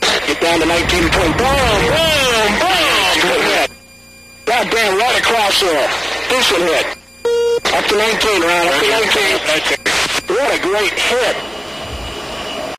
AGKillGoodBOOM.ogg